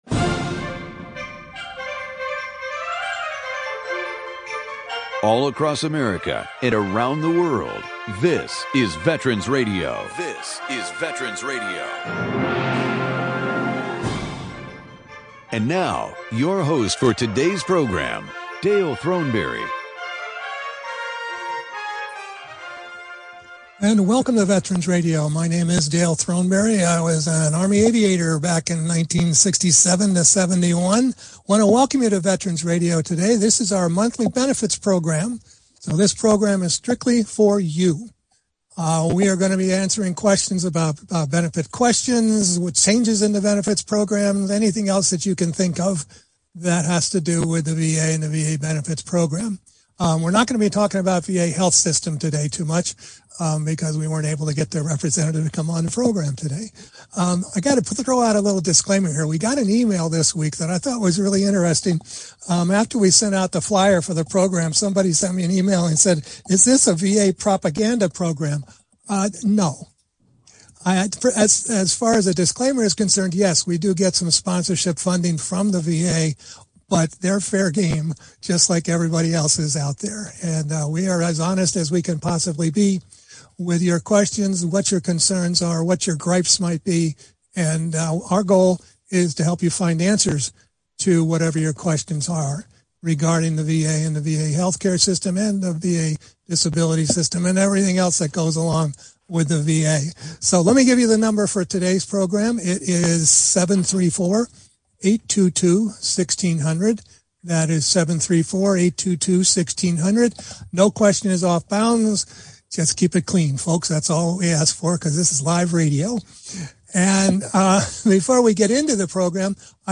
Monthly veteran benefits program with expert panel of experienced VSO and disability law representatives.